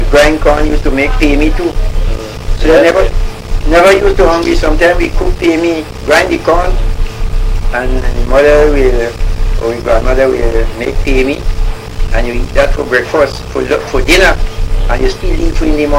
l audio cassette